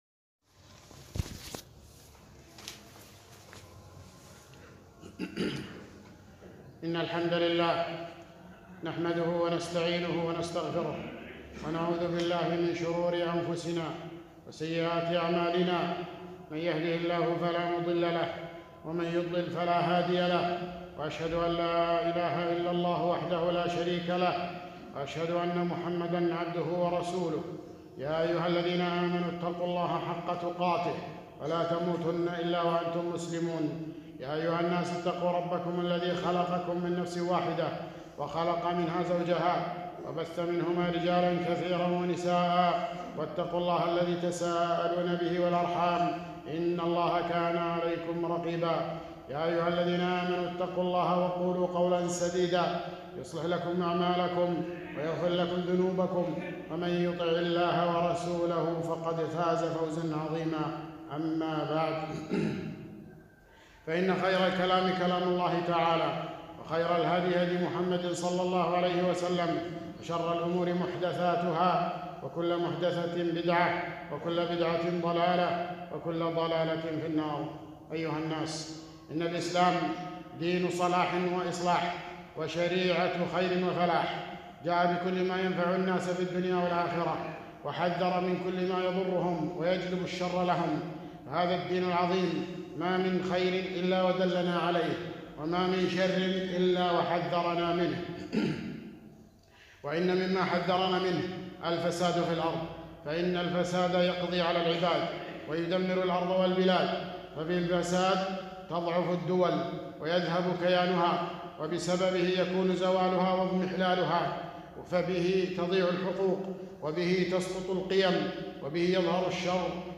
خطبة - مكافحة الفساد في الاسلام